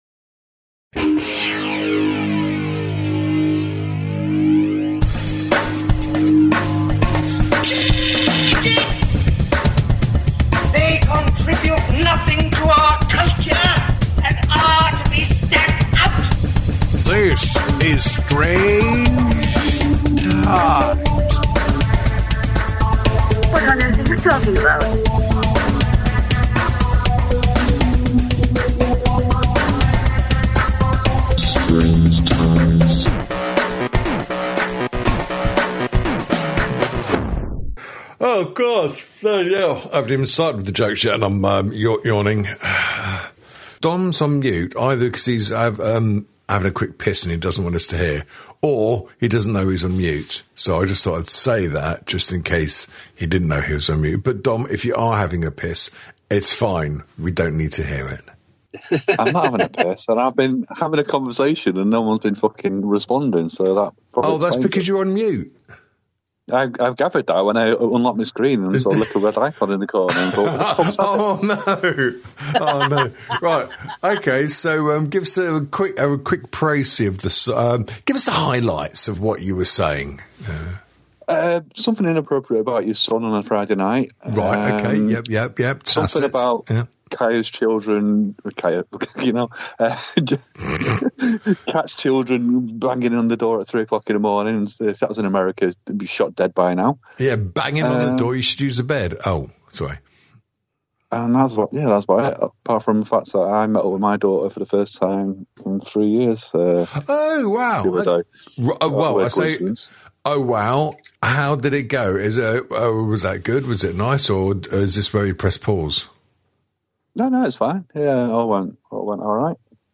A dark comedy, improvisational talk show